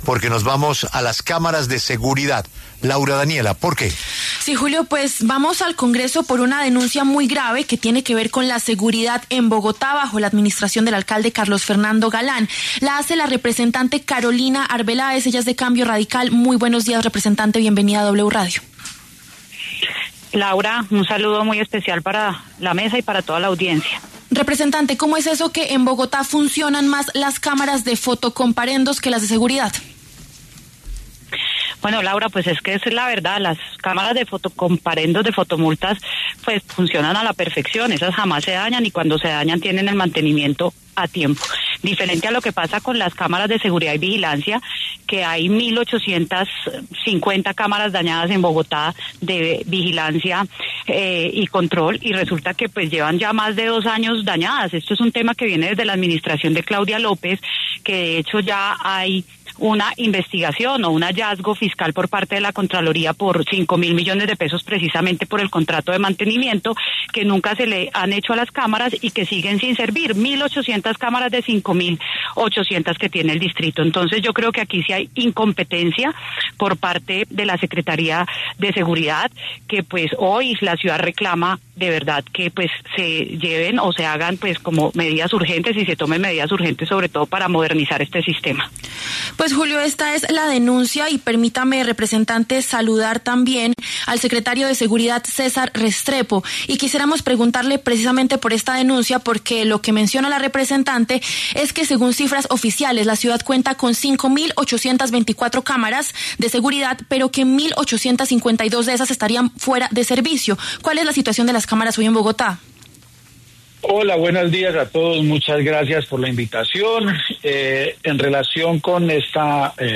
La representante Carolina Arbeláez, de Cambio Radical, y el secretario de seguridad de Seguridad de Bogotá, César Restrepo, debatieron sobre el estadio de gran parte de las cámaras en la ciudad.